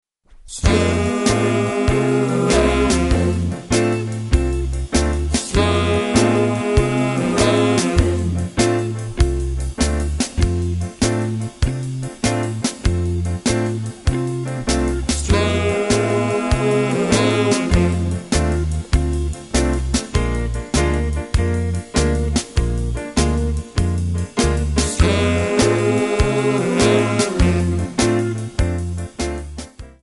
MPEG 1 Layer 3 (Stereo)
Backing track Karaoke
Pop, Oldies, 1950s